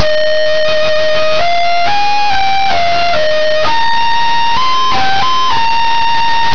Solution:  You make the files by dividing and then multiplying by 2 (7 bits), dividing and then multiplying by 4 (6 bits), etc., all the way up to dividing and then multiplying by 128, which uses only 1 bit to record each amplitude.
flute2.wav